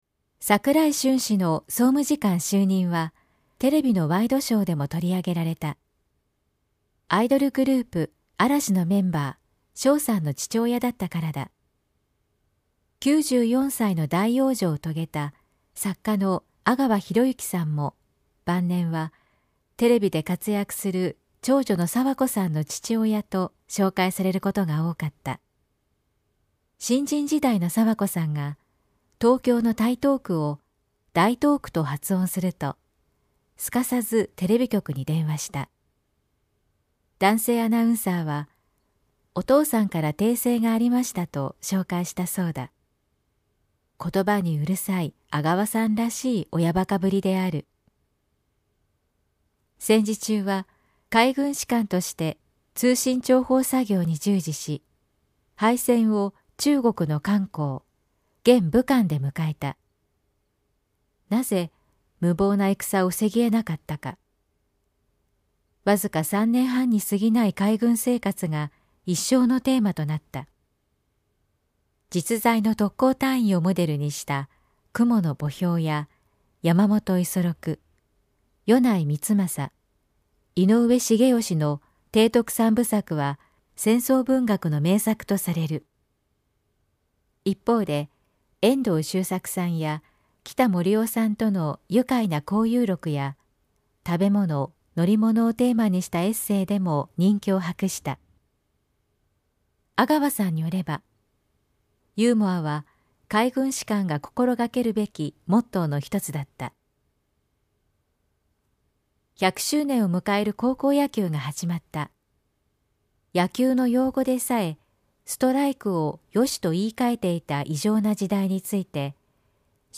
全国240名の登録がある局アナ経験者がお届けする番組「JKNTV」
産経新聞1面のコラム「産経抄」を、局アナnetメンバーが毎日音読してお届けします。